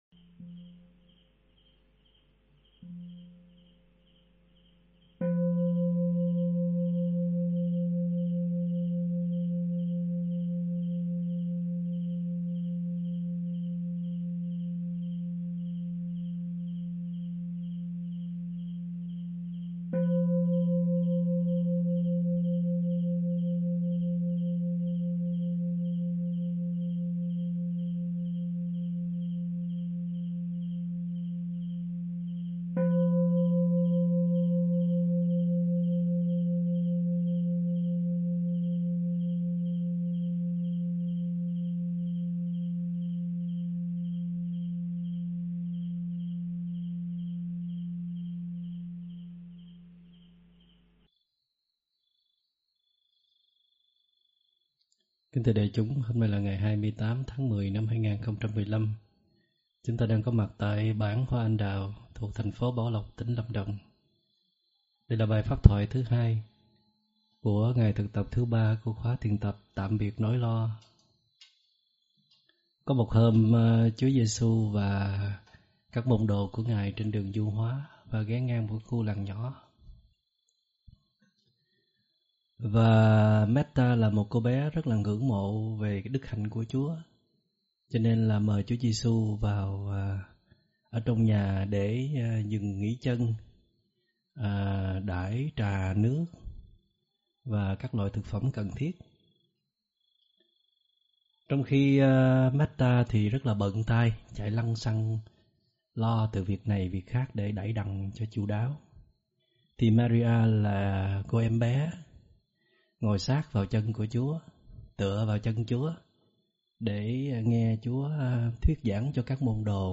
Mời quý phật tử nghe mp3 thuyết pháp Nếu chẳng một phen suơng lạnh buốt do ĐĐ. Thích Minh Niệm giảng ngày 28 tháng 10 năm 2015